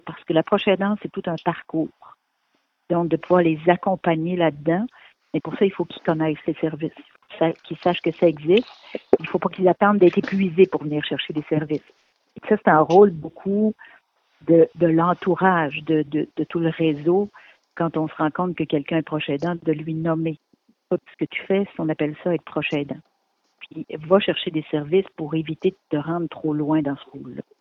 En entrevue, la dg a mentionné qu’il peut arriver qu’une personne proche aidante oublie qu’elle le soit devenue.